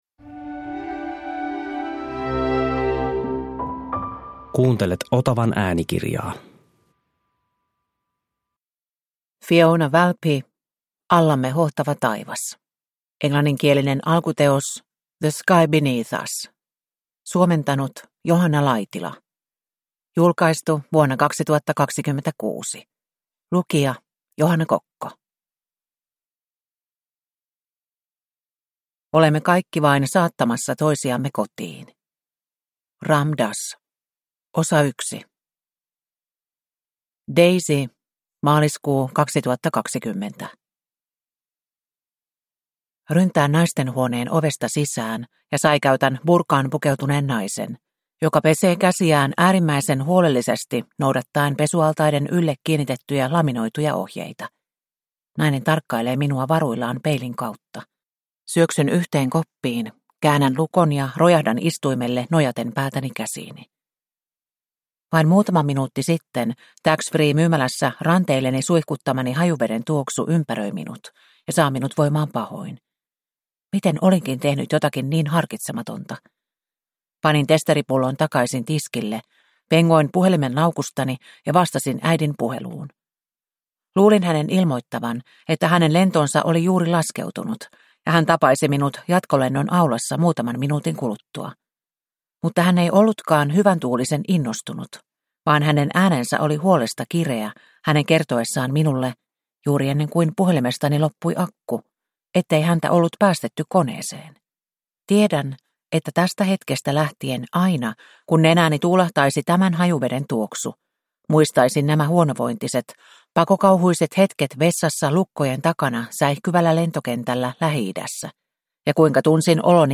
Allamme hohtava taivas (ljudbok) av Fiona Valpy